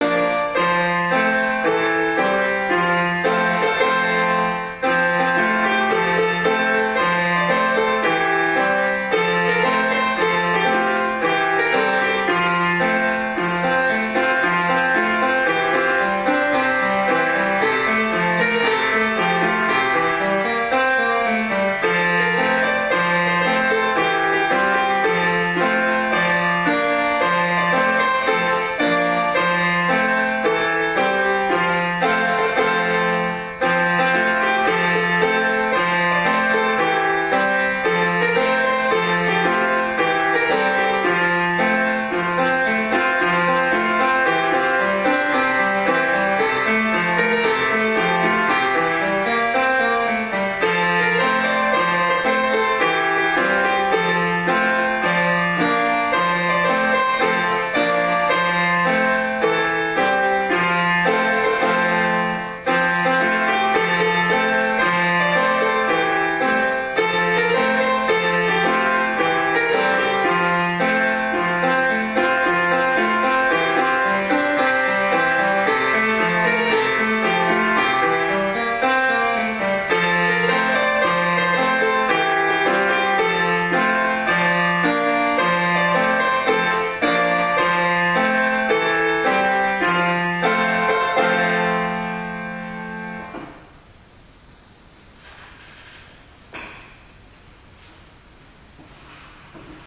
吉尾（よしお）小学校の校歌
吉尾小学校校歌.mp3